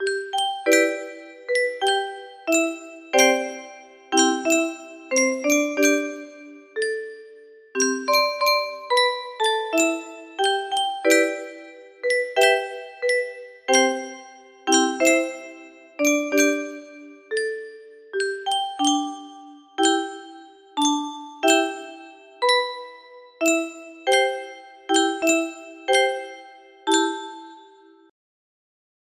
Unknown Artist - Untitled music box melody
Kikkerland 15 music boxes More